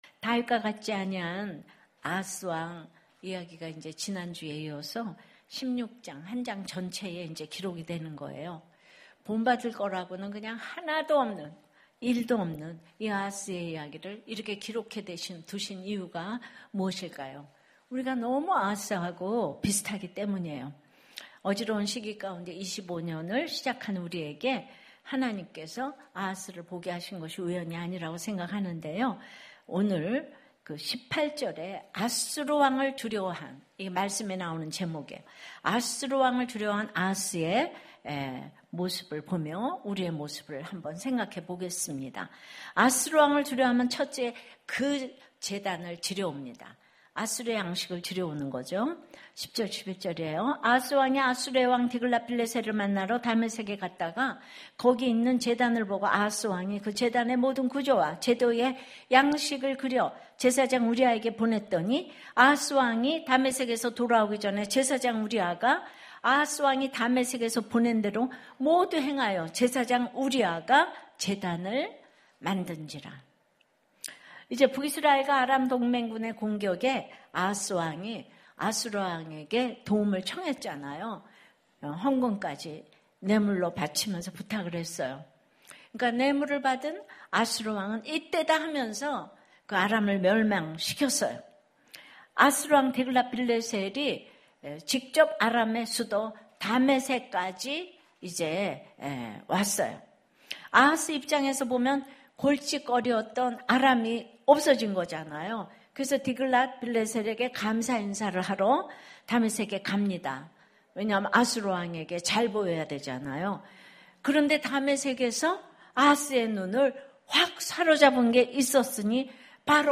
예배 주일예배